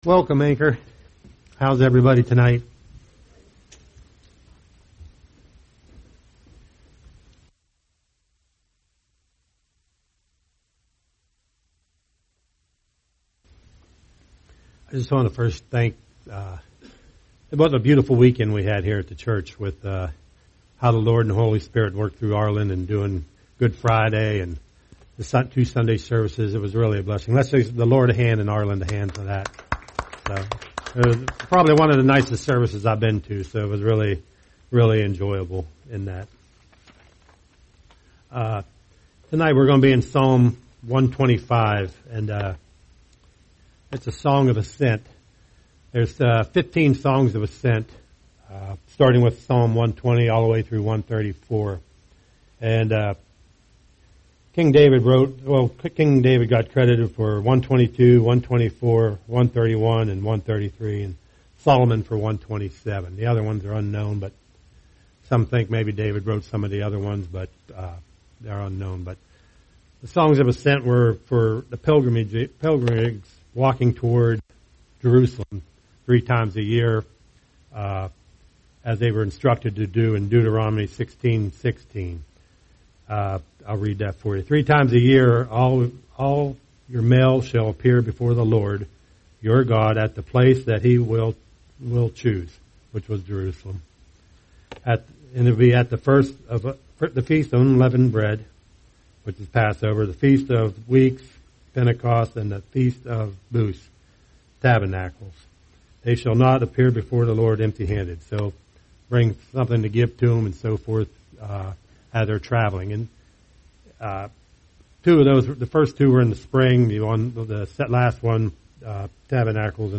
A message from the topics "The Book of Psalms."